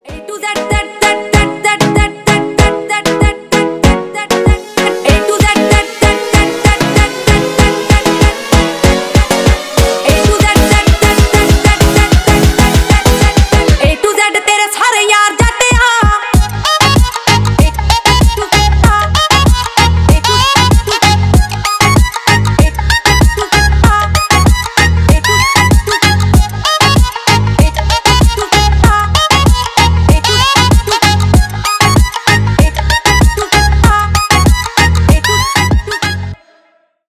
• Качество: 320 kbps, Stereo
Ремикс
Поп Музыка
Индийские